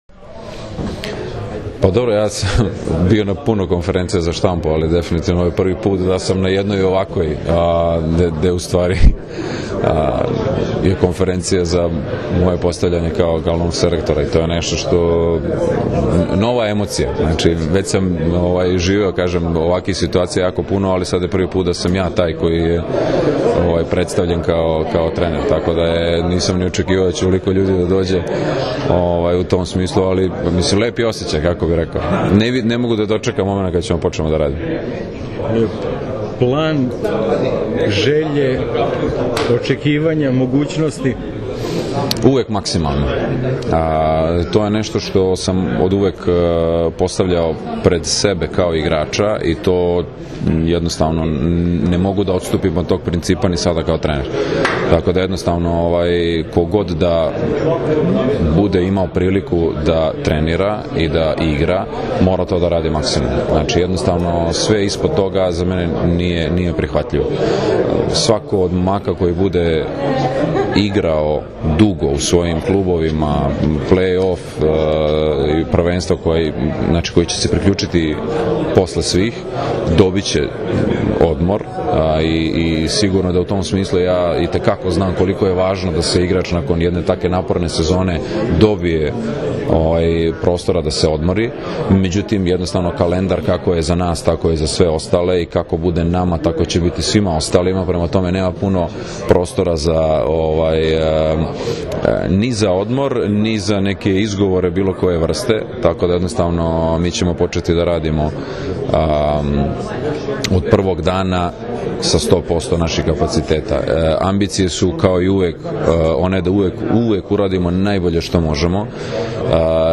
U beogradskom hotelu „Metropol“ održana je konferencija za novinare na kojoj je predstavljen Nikola Grbić, novi selektor muške seniorske reprezentacije Srbije.
IZJAVA NIKOLE GRBIĆA